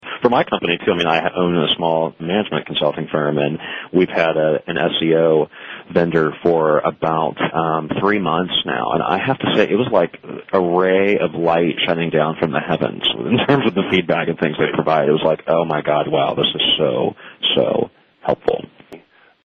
iec-testimonial.mp3